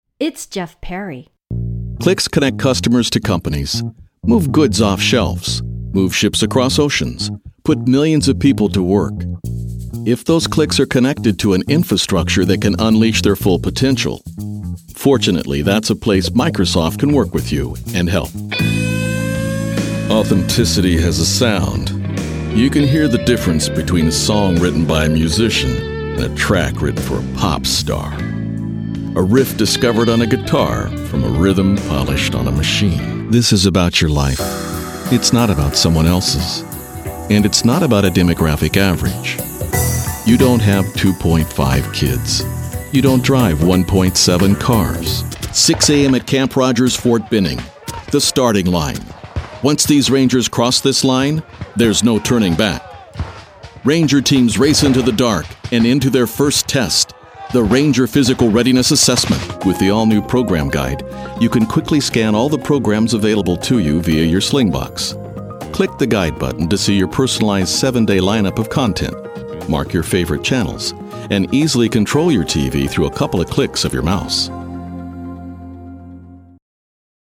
Warm and Friendly
Kein Dialekt
Sprechprobe: Industrie (Muttersprache):